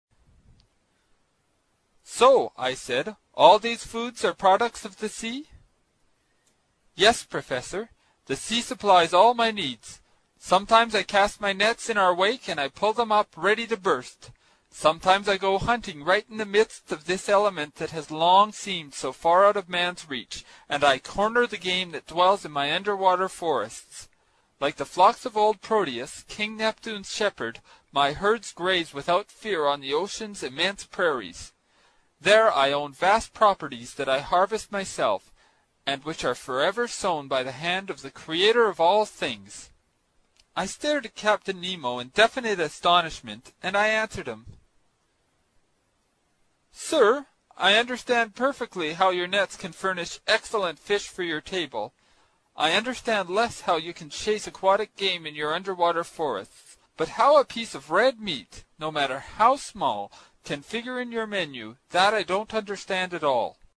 英语听书《海底两万里》第145期 第10章 水中人(16) 听力文件下载—在线英语听力室